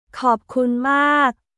コープクン・マーク